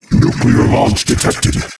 星际争霸音效-zerg-advisor-zadupd04.wav